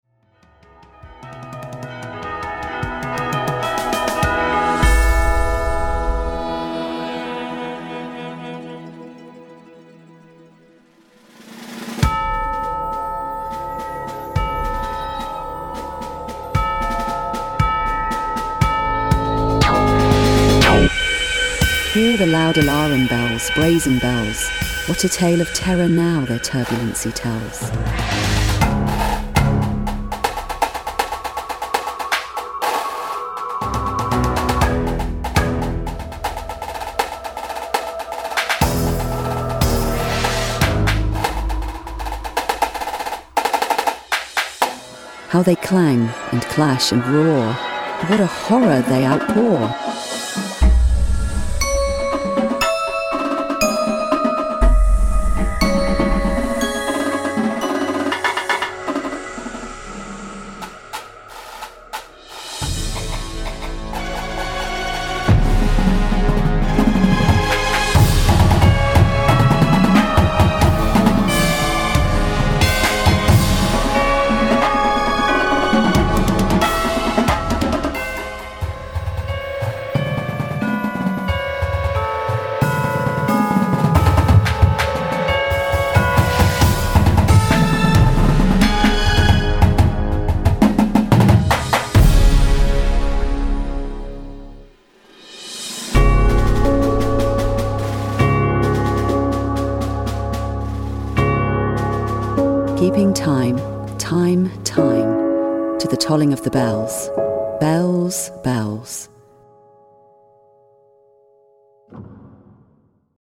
Indoor Percussion Shows
• Snares
• Tenors
• 6/5 Bass Drums
• 3 Marimbas
• Xylo/Glock
• 2 Synths
• Drumset